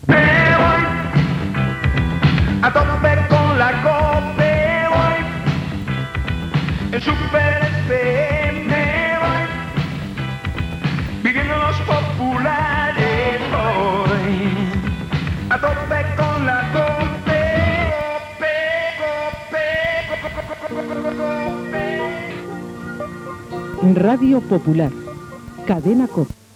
Jingle del programa